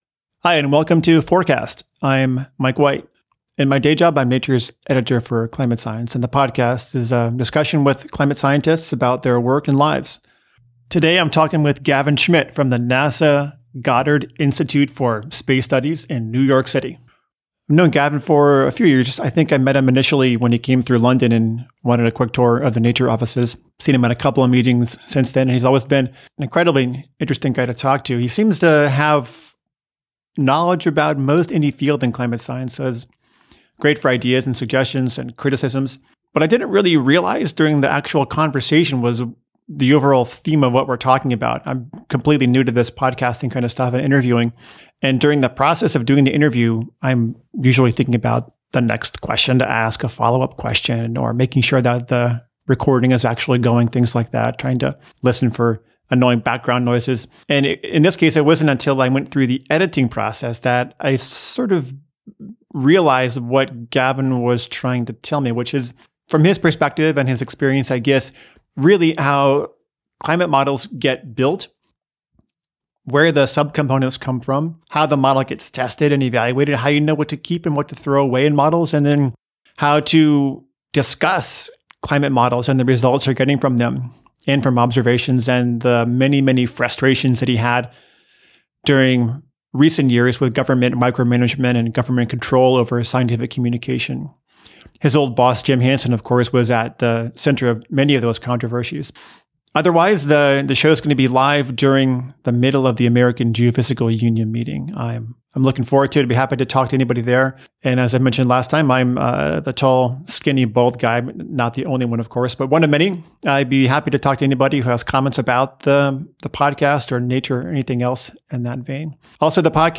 climate conversations